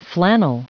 Prononciation du mot flannel en anglais (fichier audio)
Prononciation du mot : flannel